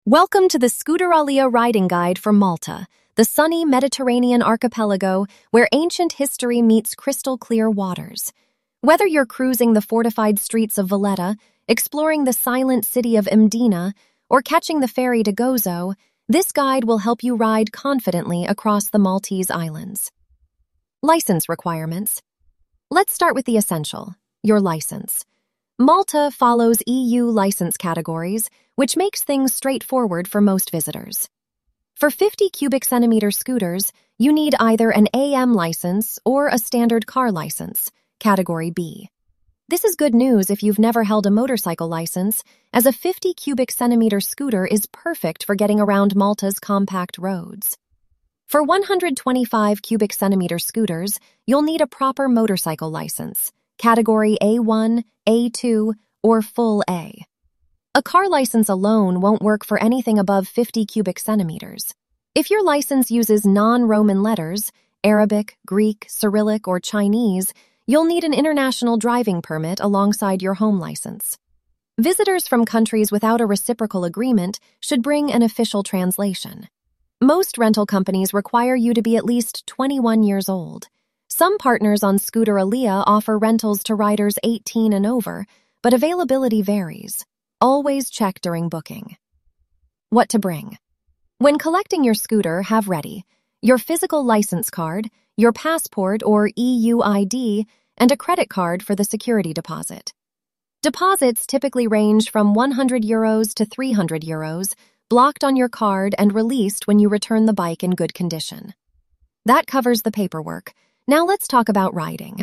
🎧 Malta Scooter Riding Audio Guide